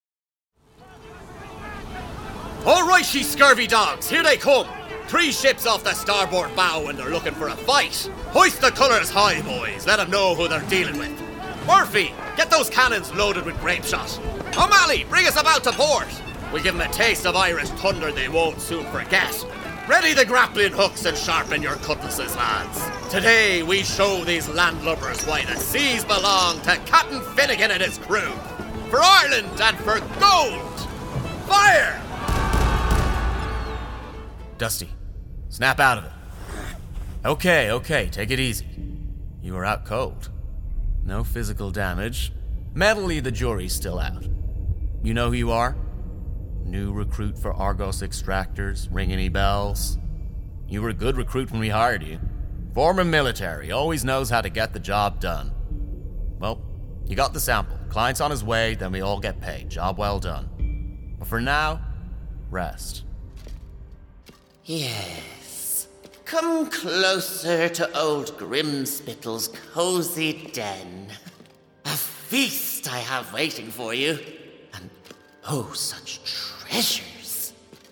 20s-30s. Male. Studio. Irish.
Computer Games